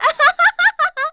Juno-Vox_Happy3.wav